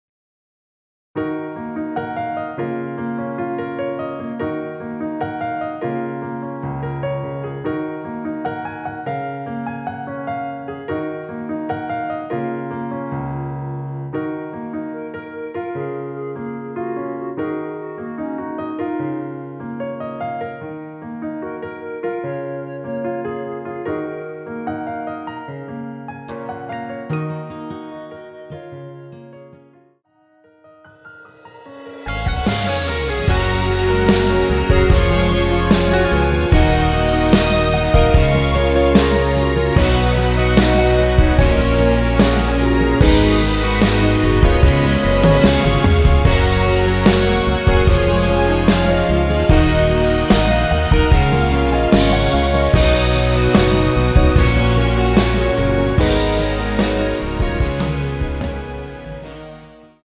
원키에서(-8)내린 멜로디 포함된 MR입니다.
Ebm
앞부분30초, 뒷부분30초씩 편집해서 올려 드리고 있습니다.
중간에 음이 끈어지고 다시 나오는 이유는